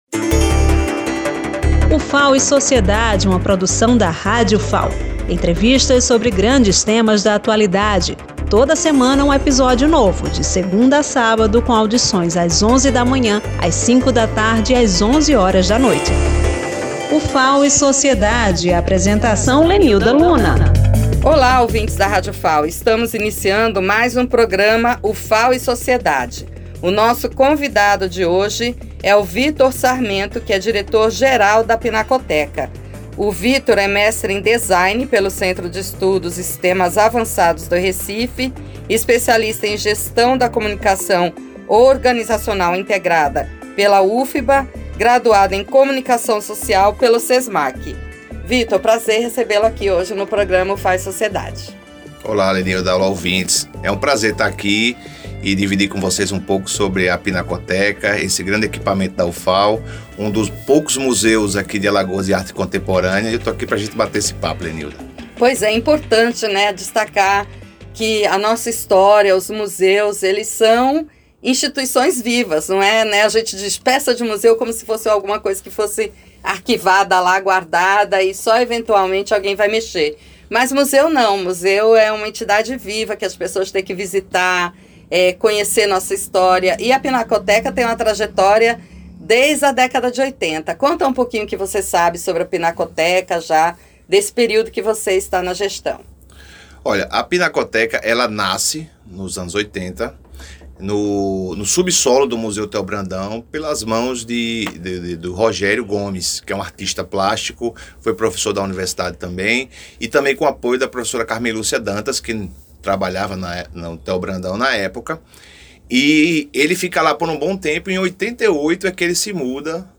em entrevista à Rádio Ufal, durante o programa Ufal e Sociedade.